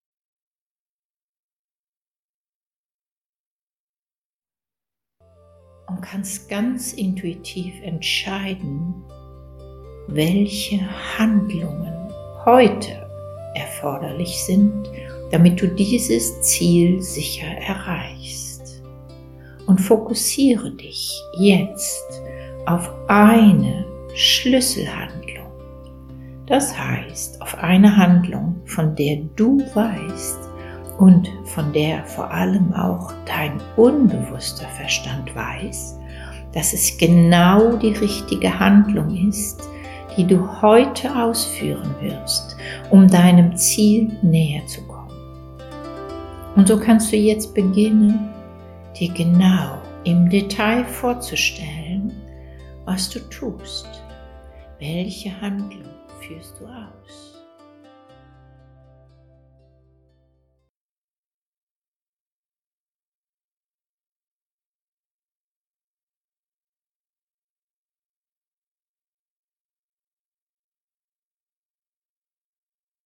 – AudioDatei zur Unterstützung Deiner täglichen Meditation